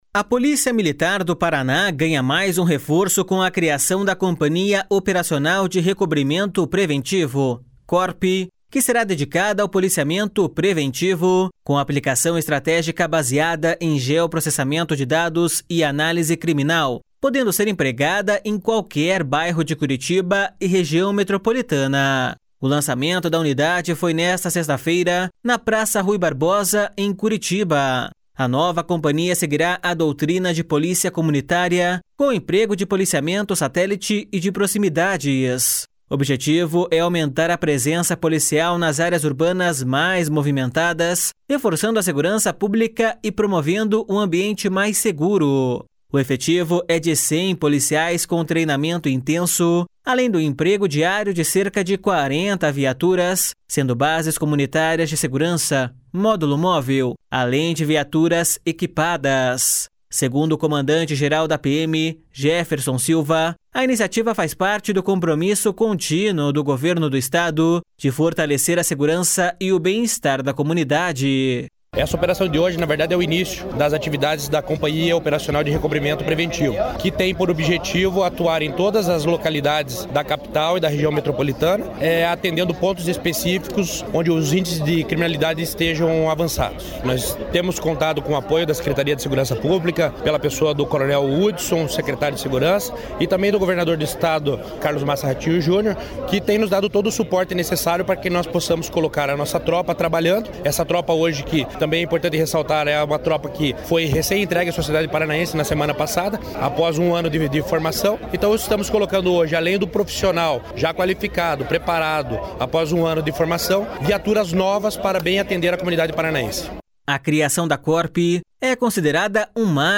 Segundo o comandante-geral da PM, Jefferson Silva, a iniciativa faz parte do compromisso contínuo do Governo do Estado de fortalecer a segurança e o bem-estar da comunidade.// SONORA JEFFERSON SILVA.//